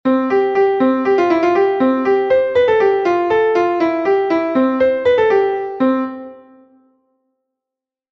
Entoación a capella
Melodía en 3/8 en Do M